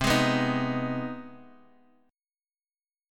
CmM7#5 chord